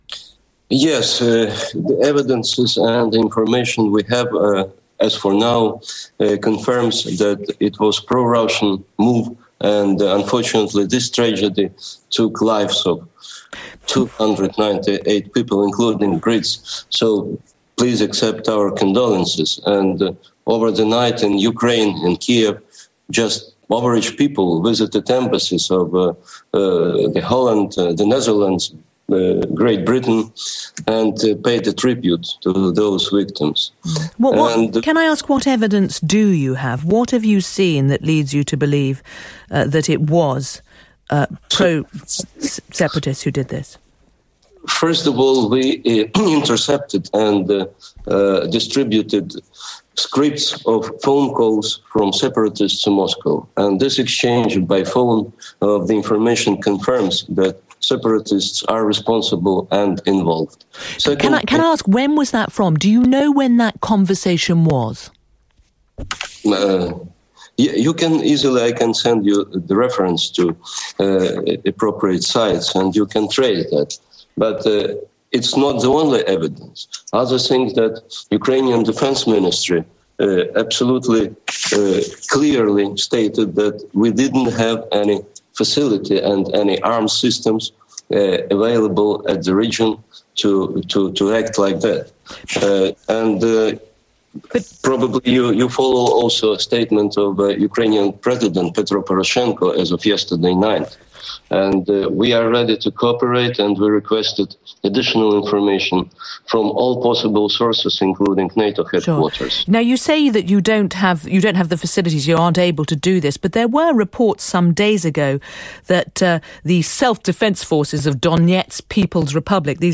Ukraine's ambassador to Nato on the BBC Radio 4's Today programme